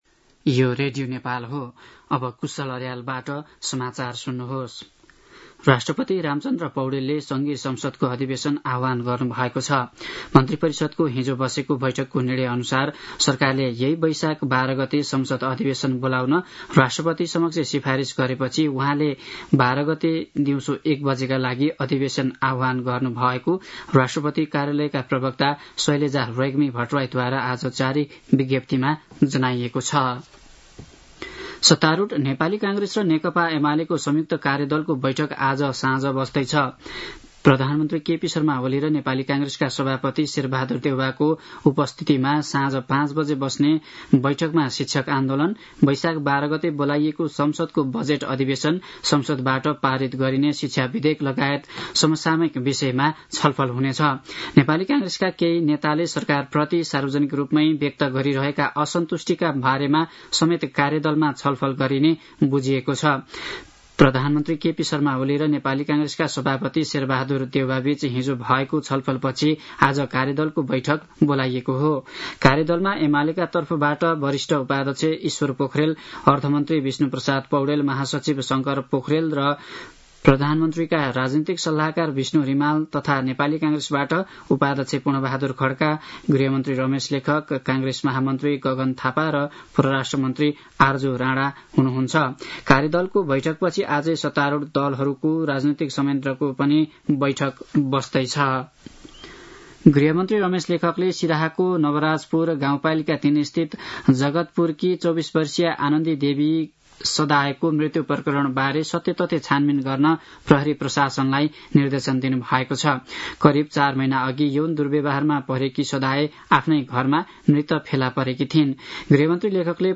दिउँसो ४ बजेको नेपाली समाचार : ३ वैशाख , २०८२